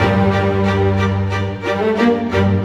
Rock-Pop 07 Strings 02.wav